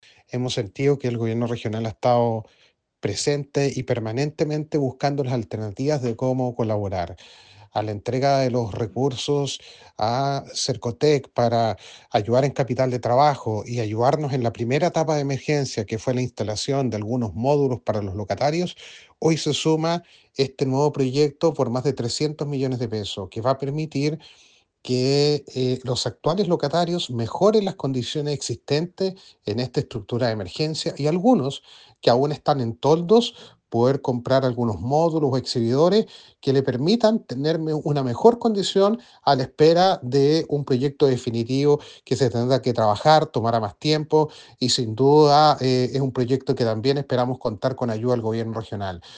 ALCALDE-ILLAPEL-DENIS-CORTES.mp3